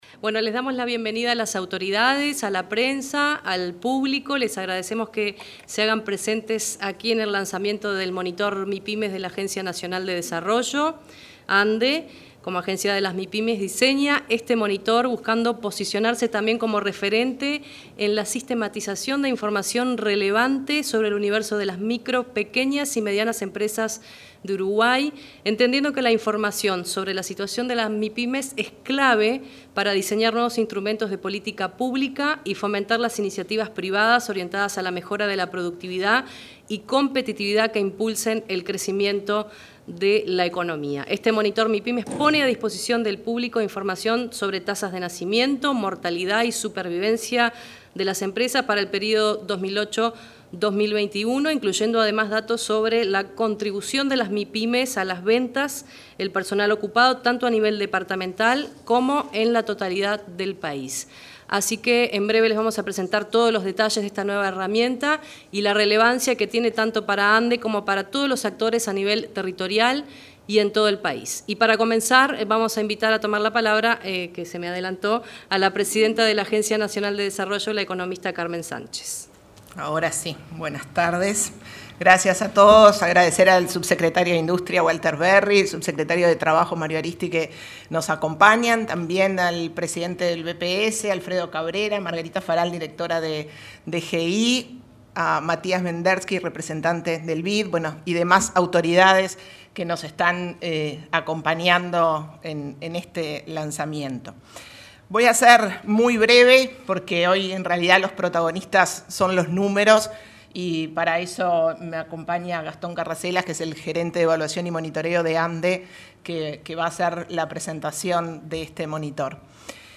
Este jueves 10, la Agencia Nacional de Desarrollo (ANDE) realizó el lanzamiento del Monitor Mipymes, en el salón de actos de la Torre Ejecutiva.